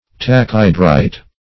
Search Result for " tachhydrite" : The Collaborative International Dictionary of English v.0.48: Tachhydrite \Tach*hy"drite\, n. [Gr. tachy`s quick + "y`dwr water.